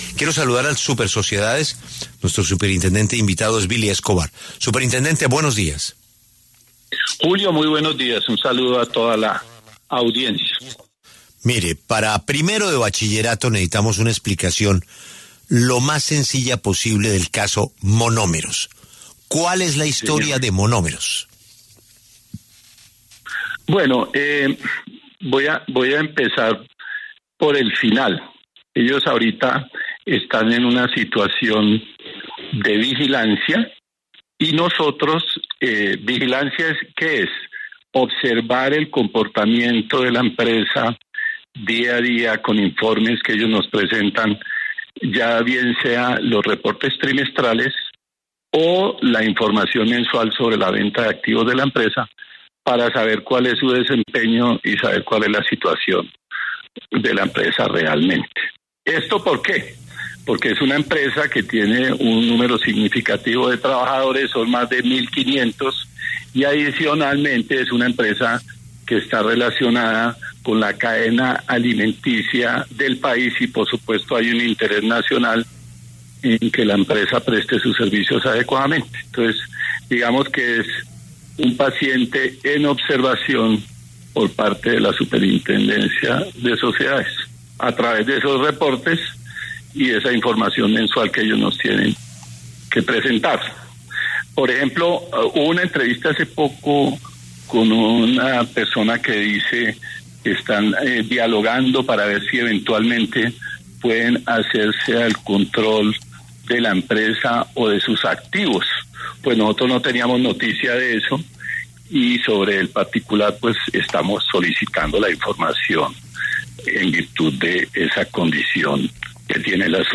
Billy Escobar, superintendente de Sociedades, afirmó en W Radio que Monómeros puede vender los bienes, pero debe garantizar el pago de las obligaciones, tanto de sus proveedores como de los trabajadores.